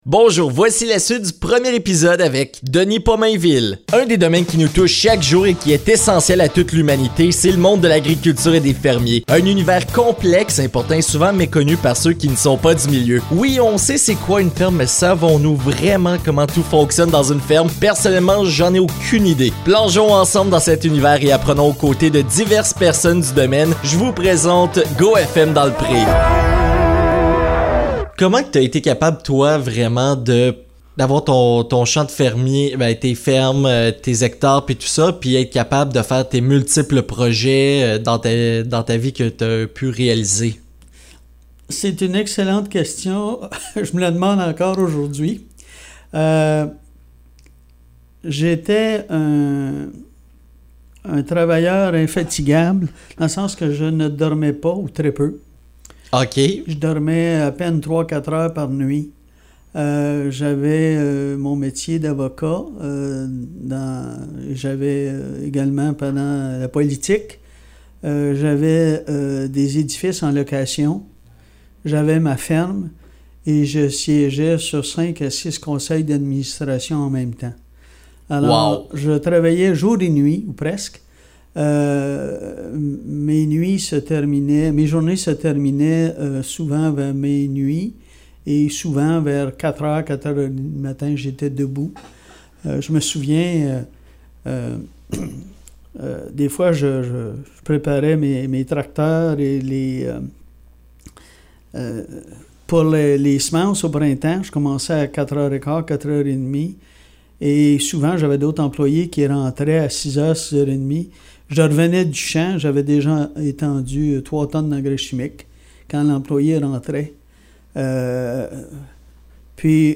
Dans cette épisode nous allons discuter avec Denis Pommainville, l'ancien maire de la Nation.